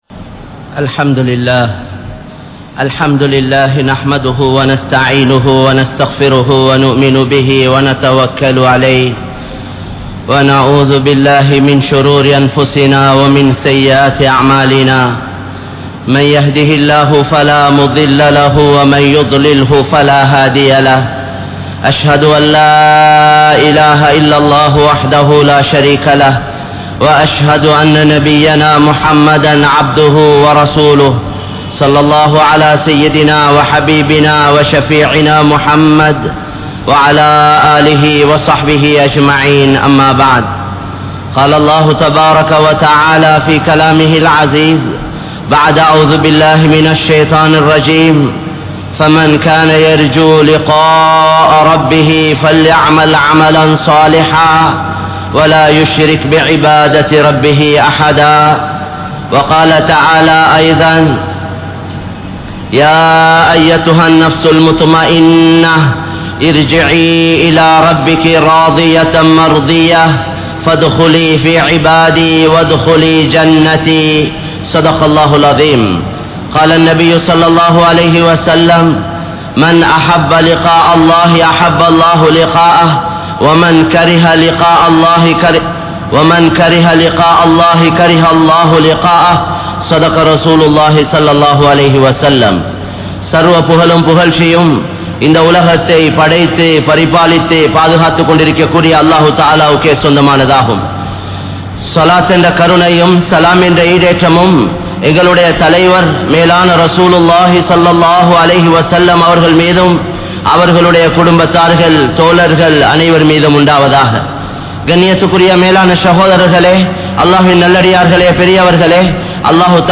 Haraaththil Ulaiththu Vaalfavarhal (ஹராத்தில் உழைத்து வாழ்பவர்கள்) | Audio Bayans | All Ceylon Muslim Youth Community | Addalaichenai
Kollupitty Jumua Masjith